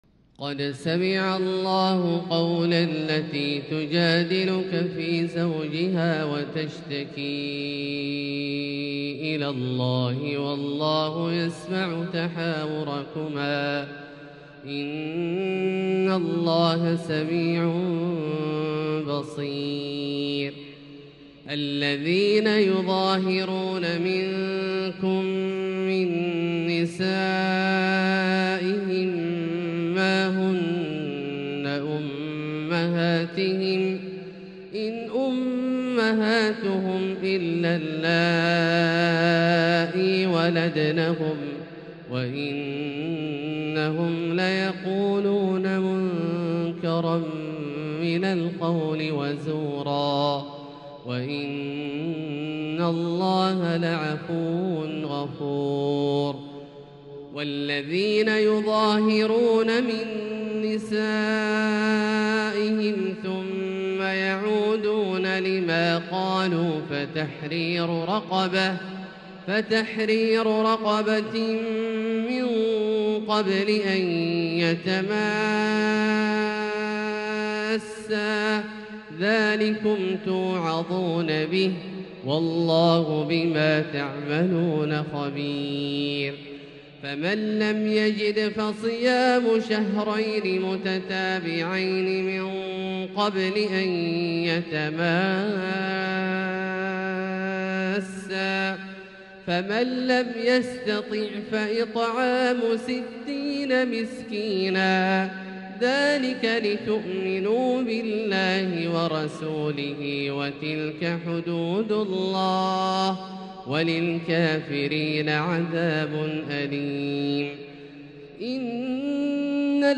تلاوة بديعة لـ سورة المجادلة كاملة للشيخ د. عبدالله الجهني من المسجد الحرام | Surat Al-Mujadilah > تصوير مرئي للسور الكاملة من المسجد الحرام 🕋 > المزيد - تلاوات عبدالله الجهني